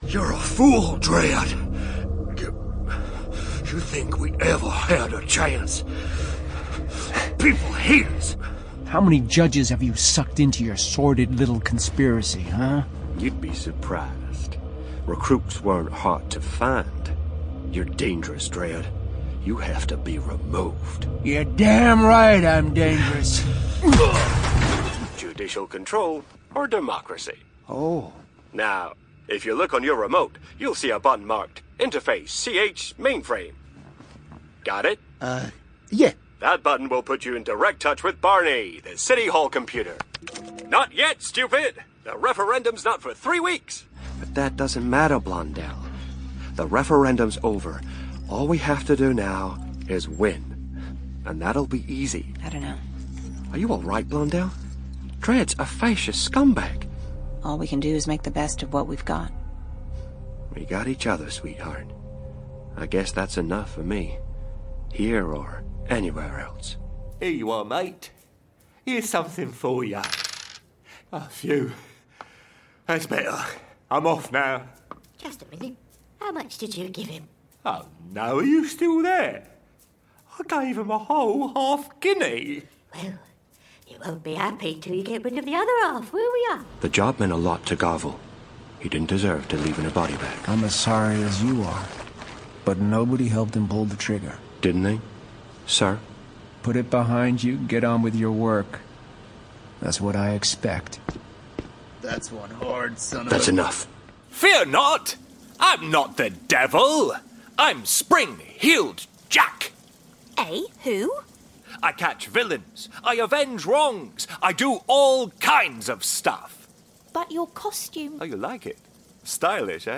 Audio Drama Showreel
A charming cheeky soft-spoken Scot, with a voice like being hugged by a hot toddy.
Male
Scottish
Gravitas
Smooth